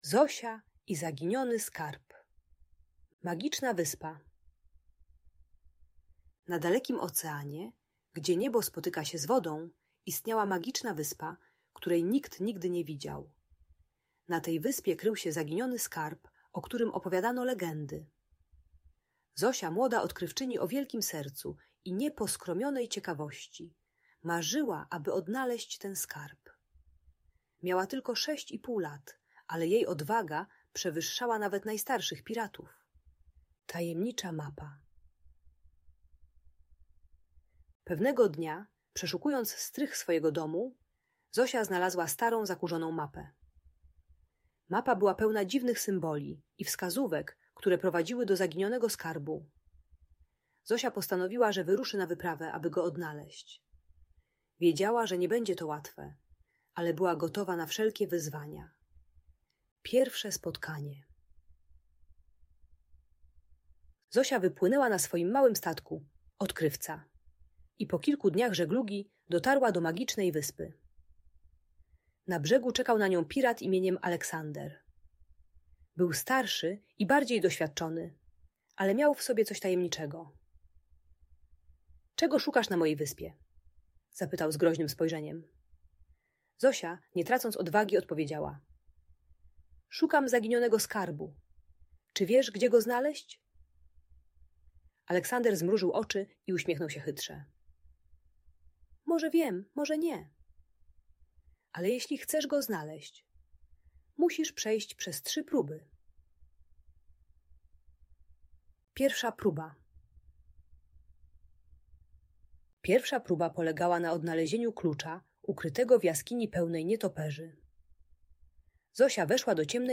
Zosia i Zaginiony Skarb - Audiobajka